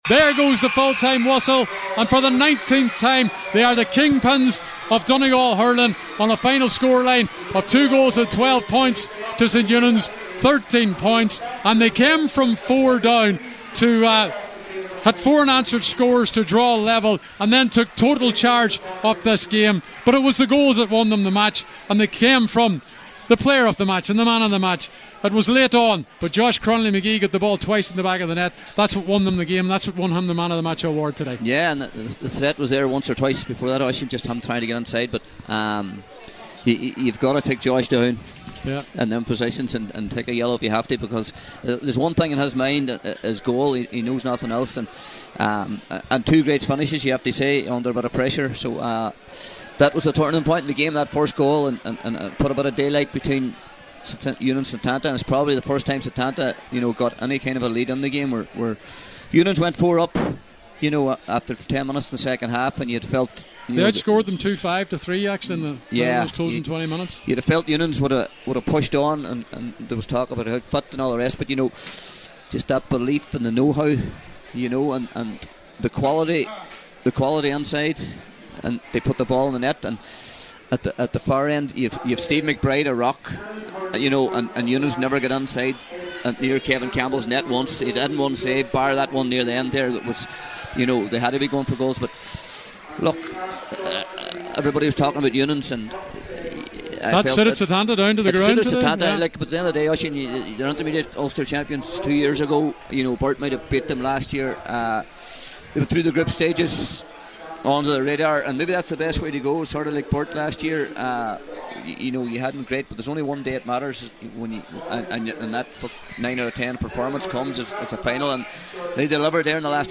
full time report from O’Donnell Park…